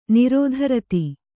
♪ nirōdha rati